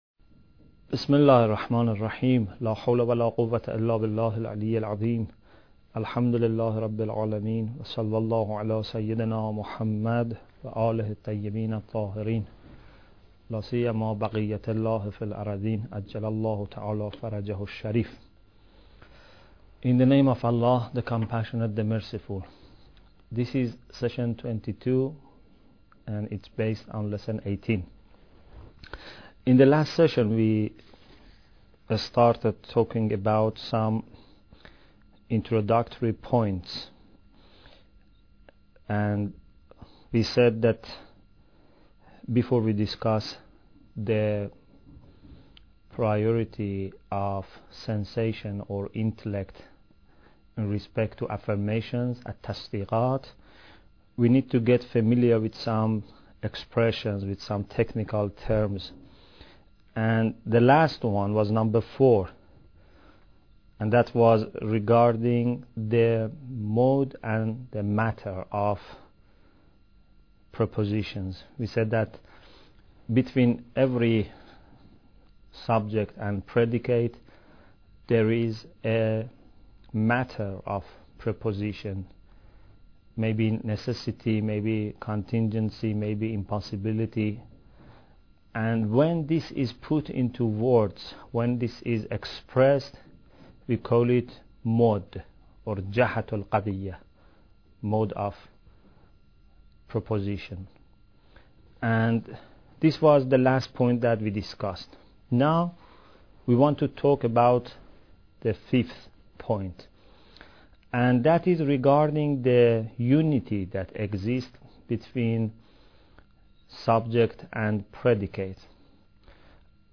Bidayat Al Hikmah Lecture 23